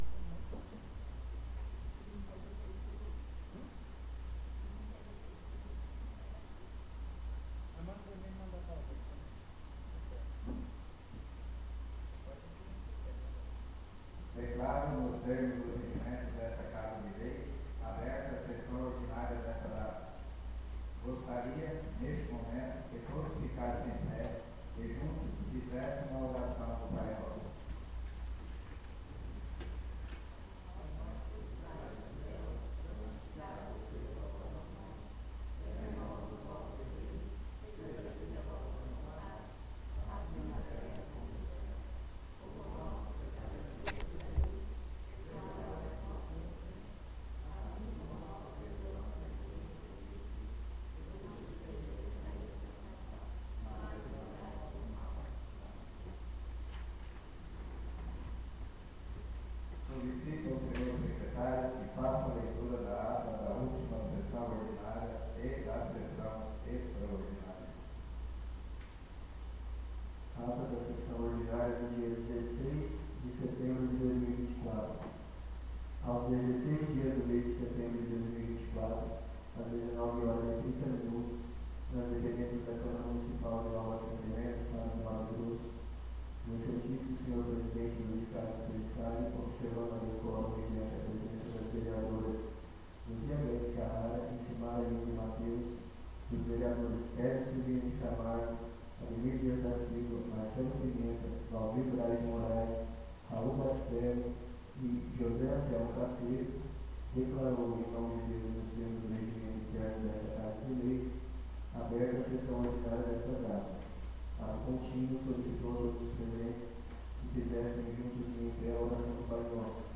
ÁUDIO SESSÃO 15-10-24 (DEFEITO)